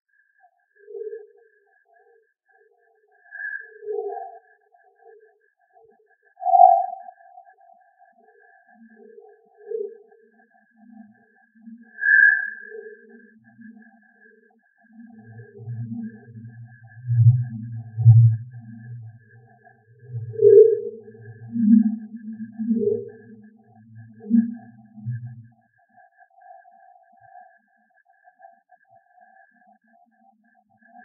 On peut écouter des données extraites d’un spectromètre de masse (intensité de 5 ions d’un extrait de sarments de vigne en HPLC-MS) : Représentation graphique d’une acquisition HPLC Masse